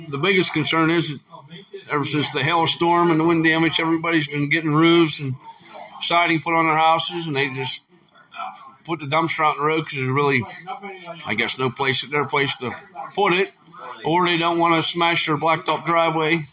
Supervisors Chairman Matt Housholder said he believes that there may not be a place for them on the property.